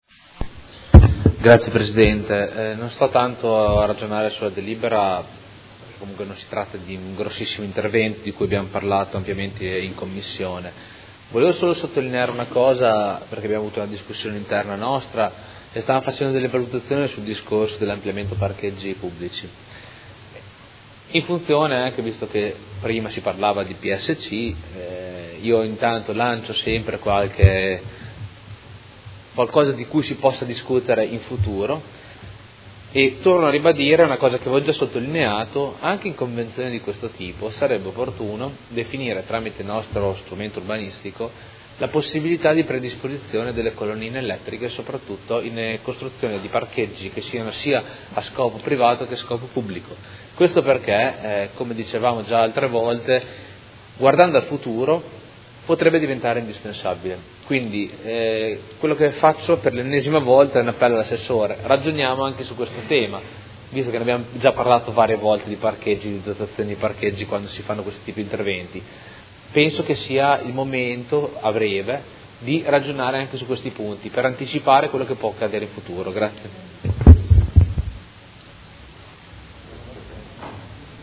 Seduta del 2/2/2017.
Dibattito